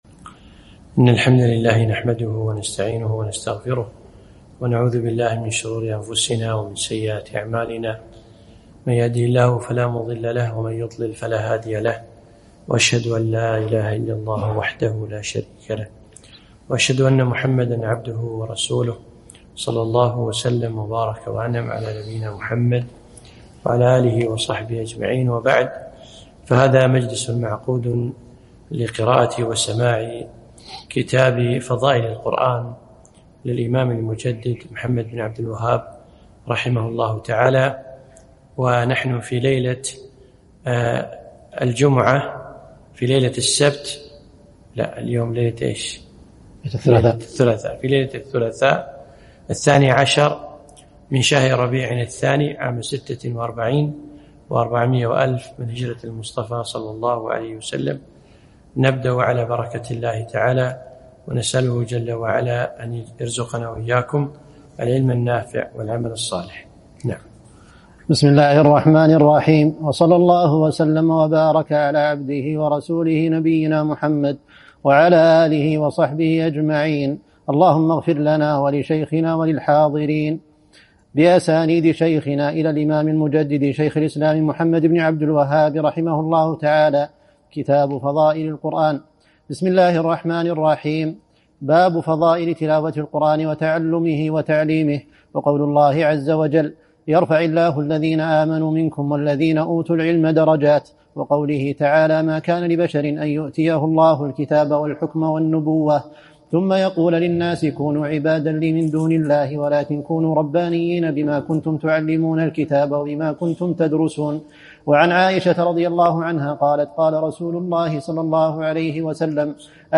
مجلس سماع رسالة (فضائل القرآن) للإمام محمد بن عبدالوهاب بمسجد معقل بن يسار ١٤٤٦ هـ